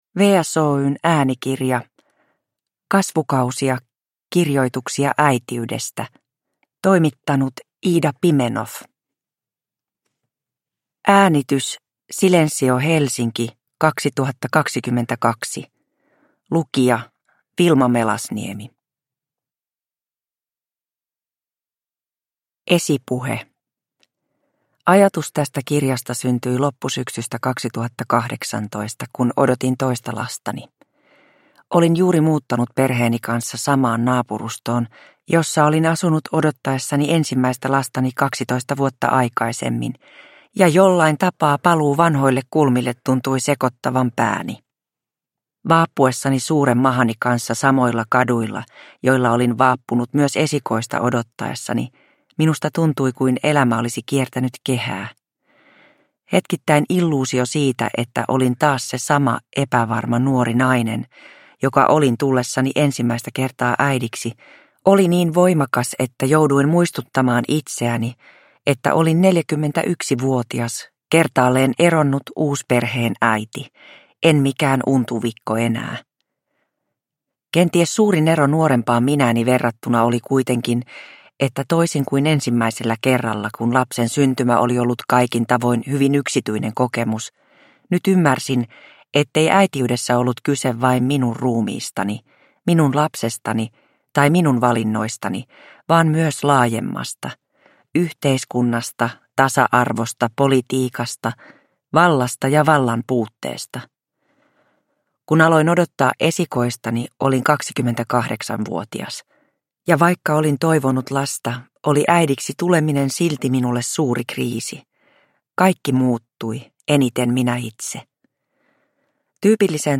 Kasvukausia – kirjoituksia äitiydestä – Ljudbok – Laddas ner